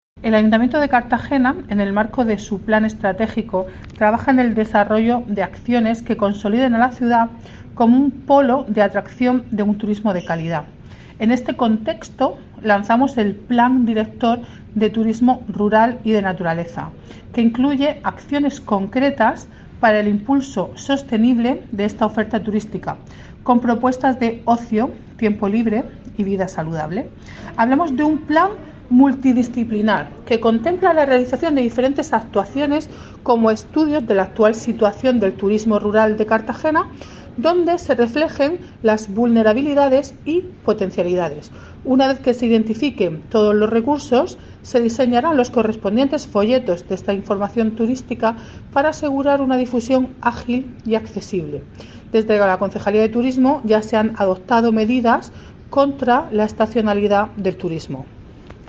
Enlace a Declaraciones de la concejal delegada de Turismo, Beatriz Sánchez, sobre el Plan Director de Turismo Rural